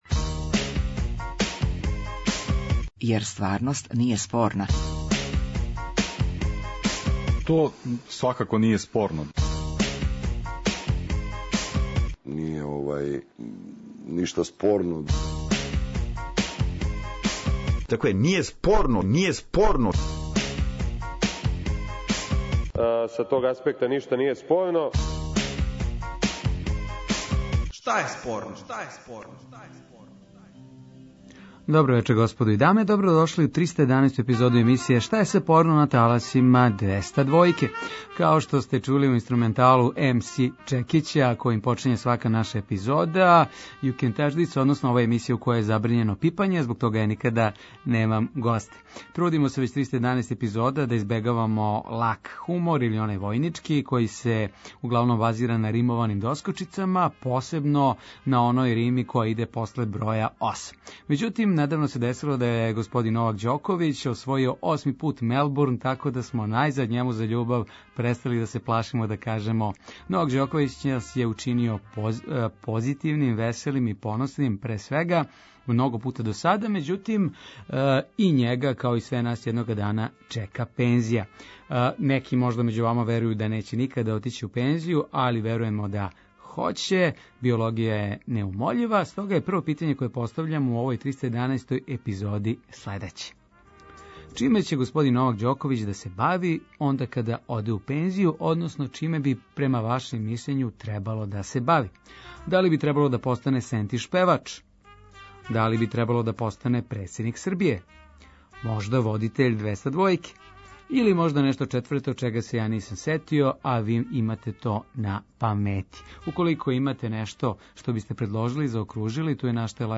Радијски актуелно - забавни кабаре интерактивног карактера